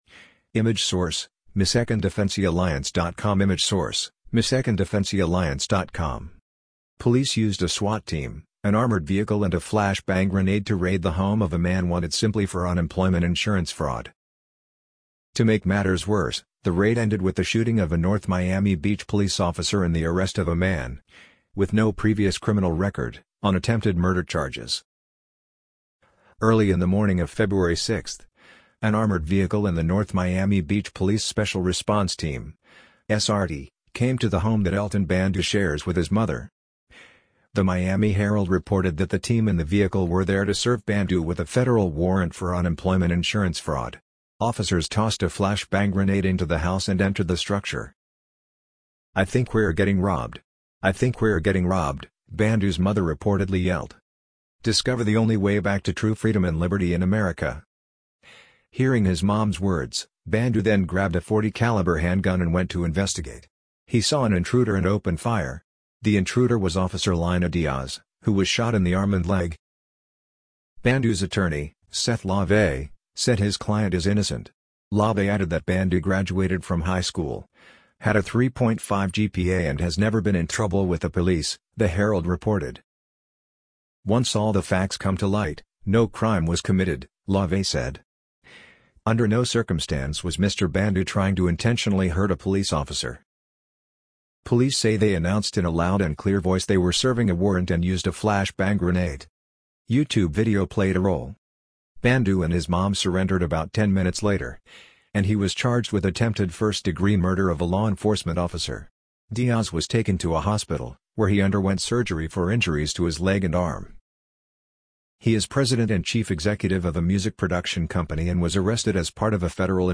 amazon_polly_54465.mp3